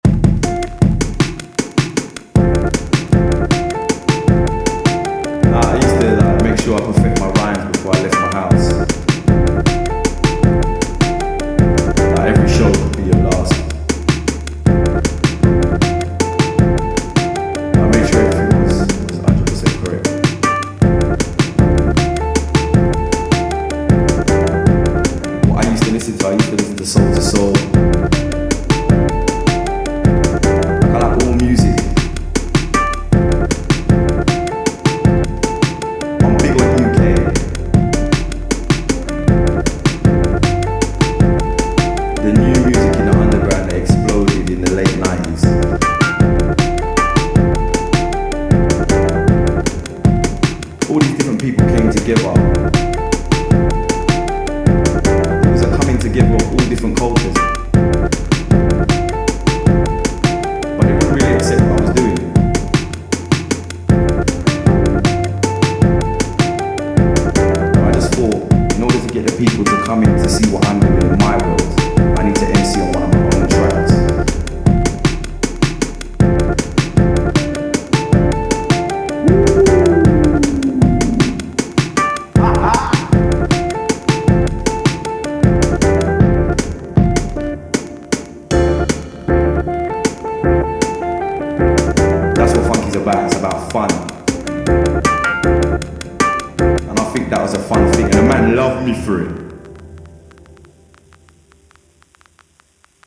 From the sounds of this track, it’s definitely sounds like a summertime thing!
love the sample flipping and chopping!
Who is that talking through the track?